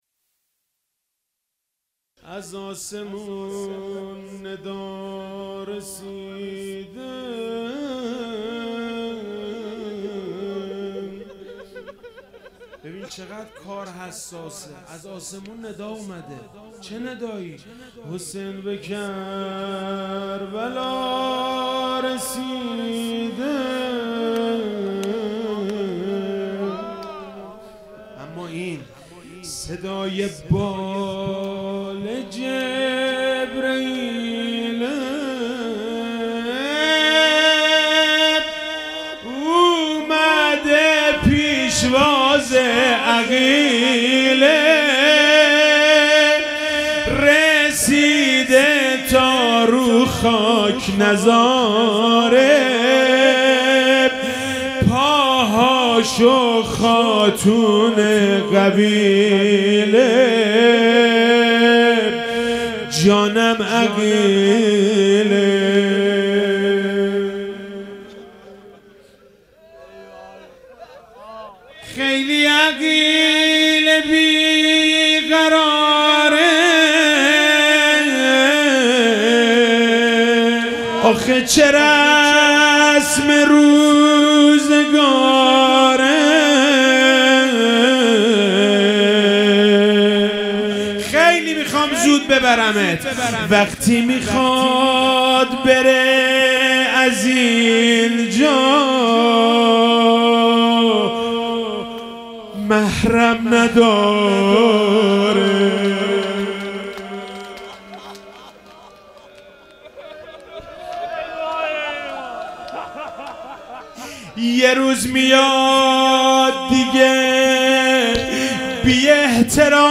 محرم99